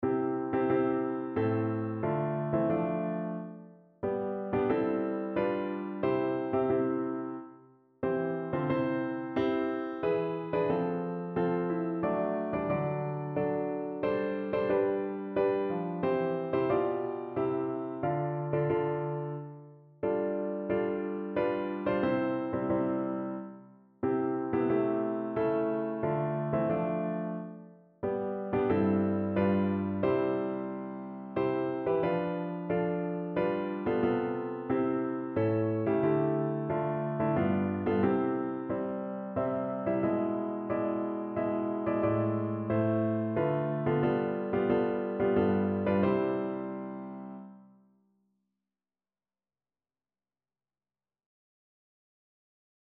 Evangeliumslieder
Notensatz (4 Stimmen gemischt)